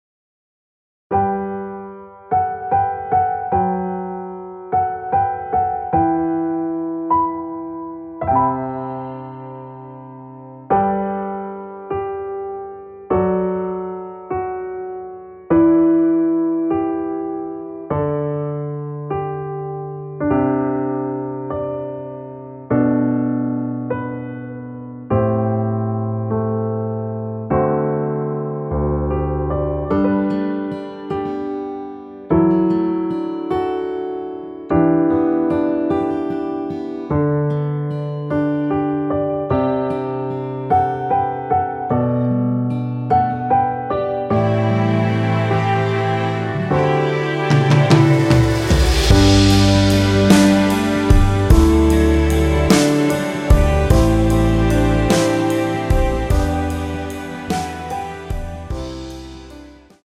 끝없는 밤을 걷자후에 2절의 나의 모든 날들을로 진행이 됩니다.
앞부분30초, 뒷부분30초씩 편집해서 올려 드리고 있습니다.
중간에 음이 끈어지고 다시 나오는 이유는